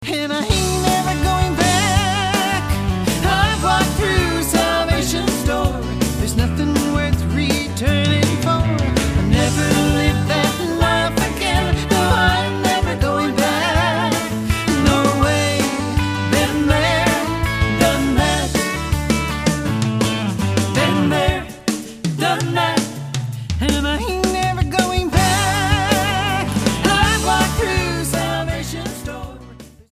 STYLE: Country
The album kicks off with the bright toe tapping
has a strong singing voice
the backing is pleasant modern country